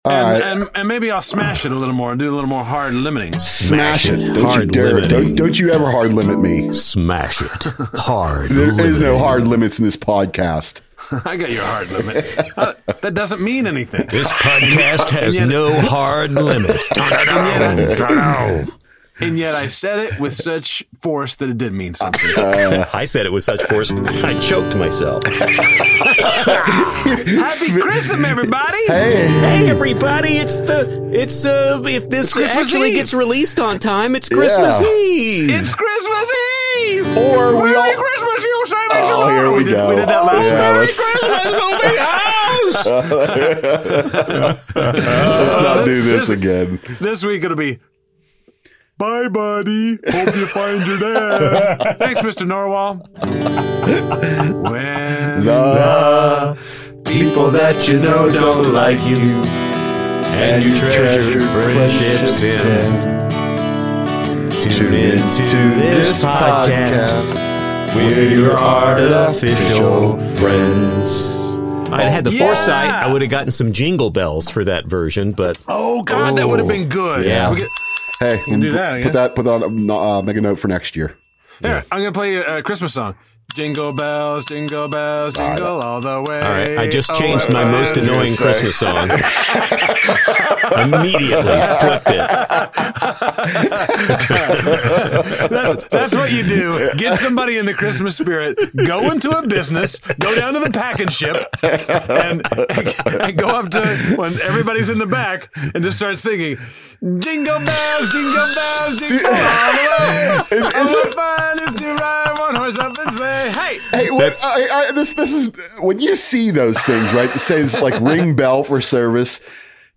You'll hear our least favorite holiday songs ever, your YAF Line calls, and the real reason why no creature was stirring -- especially a mouse.